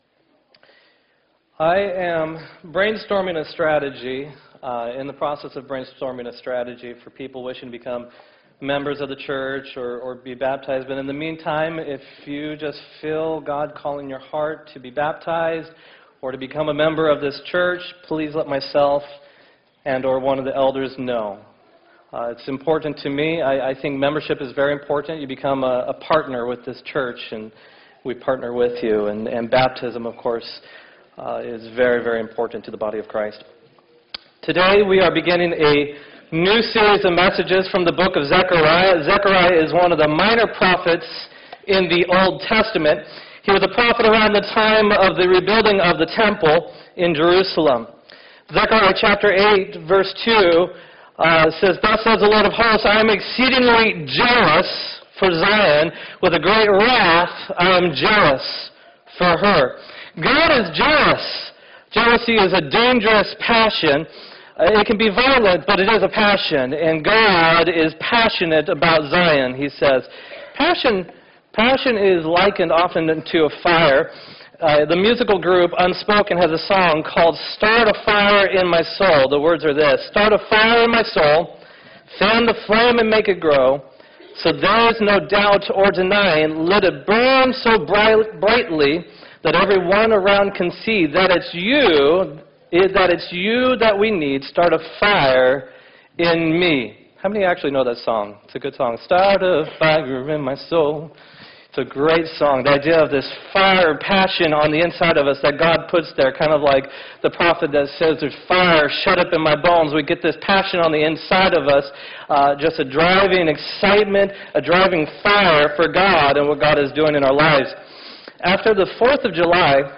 9-5-2015 sermon